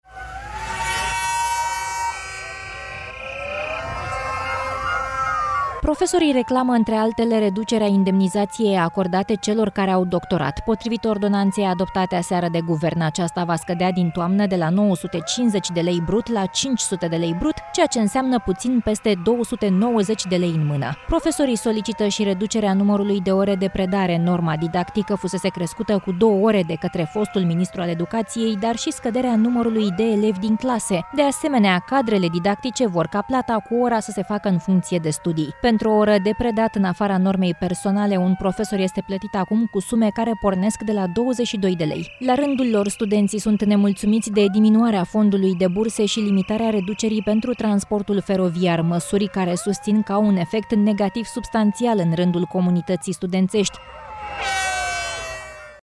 Un protest al profesorilor este în desfășurare la această oră, în fața Palatului Cotroceni.